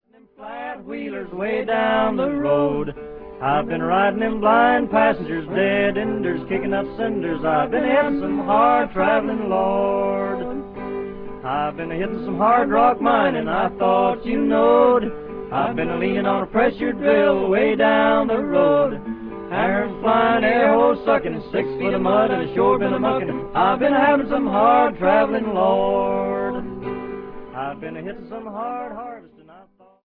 guitar
harmonica
mandolin
Recorded in New York between 1944 and 1949.